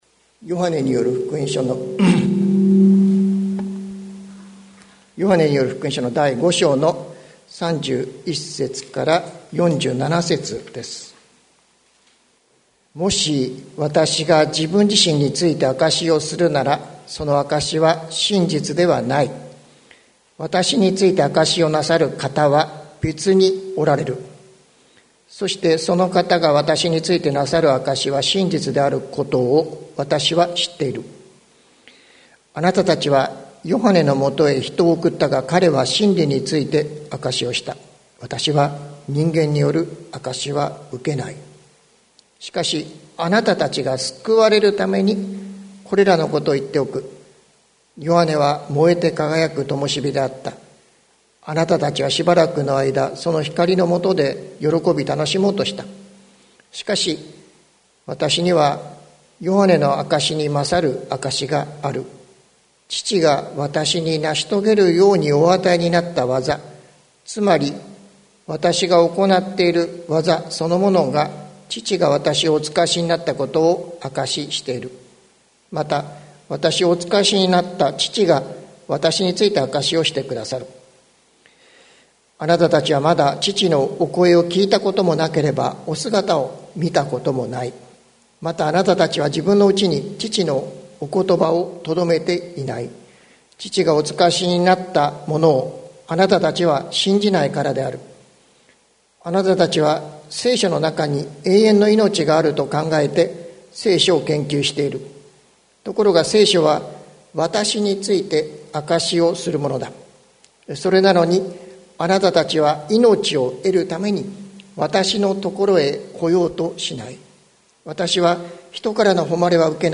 2022年03月27日朝の礼拝「イエスについての証」関キリスト教会
説教アーカイブ。